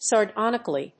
音節sar･do･ni･cal･ly発音記号・読み方sɑːrdɑ́nɪk(ə)li| -dɔ́-
• / sɑˈrdɑnɪkʌli(米国英語)
• / sɑ:ˈrdɑ:nɪkʌli:(英国英語)
sardonically.mp3